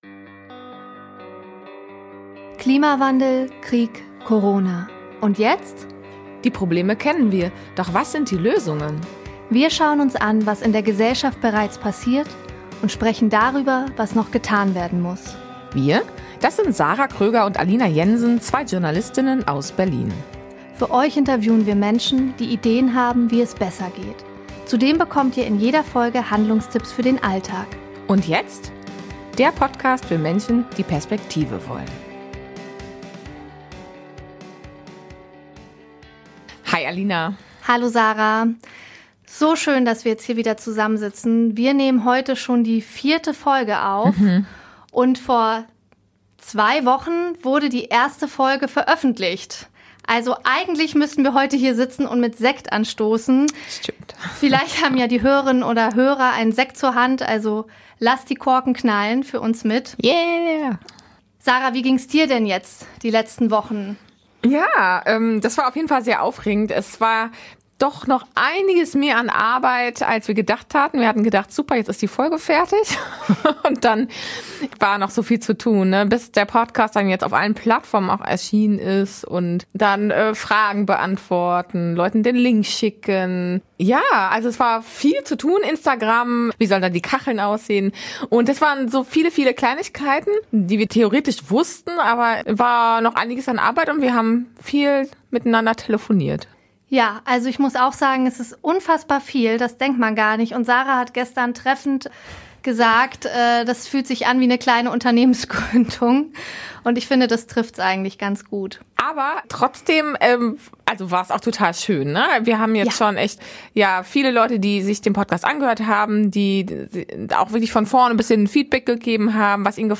Im Anschluss reflektieren wir das Interview und haben wieder eine „Community-Challenge“ parat, in der es darum geht, was wir zur positiven Entwicklung der Landwirtschaft beitragen können.